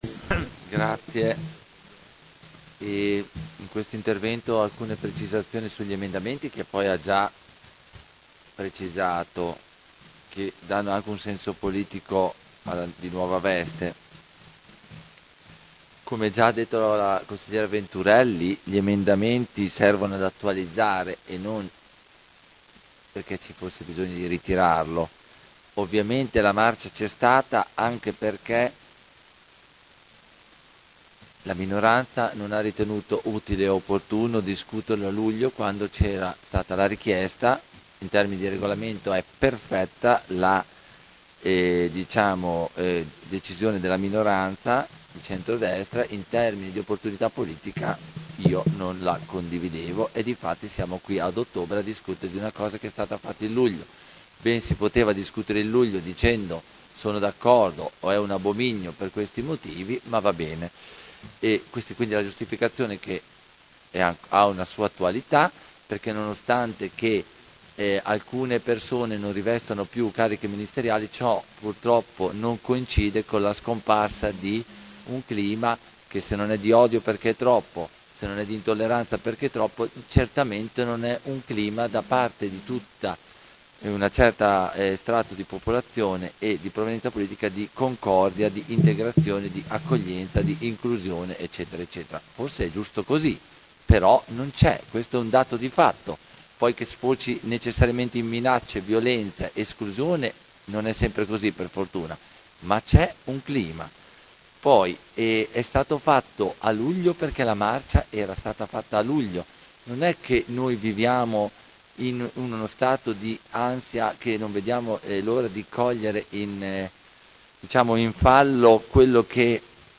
Seduta del 17/10/2019 Dibattito.